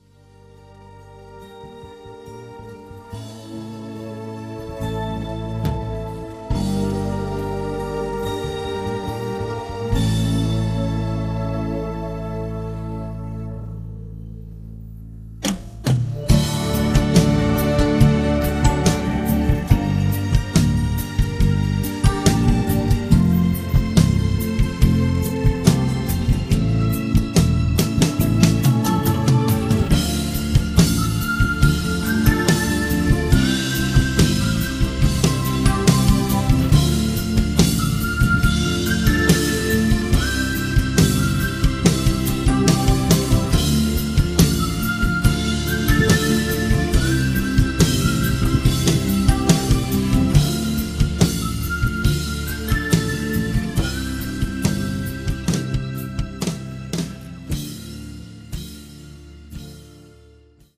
음정 -1키 5:49
장르 가요 구분 Voice Cut